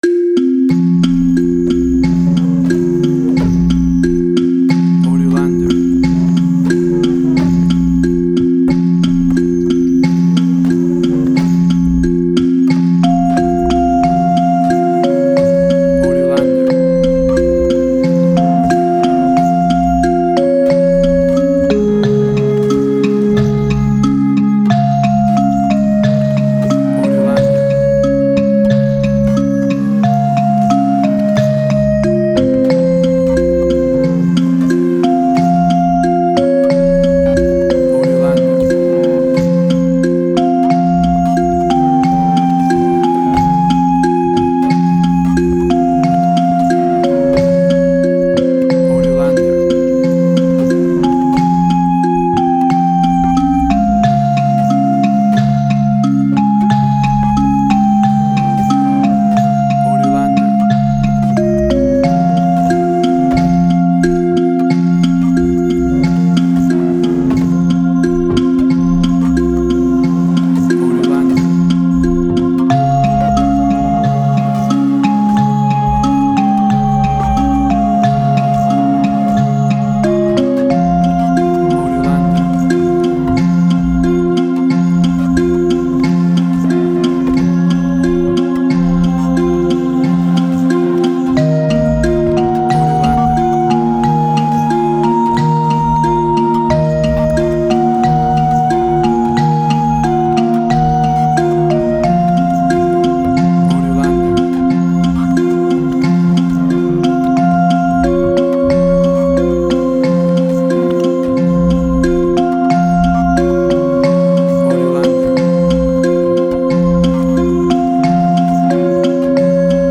Tempo (BPM): 89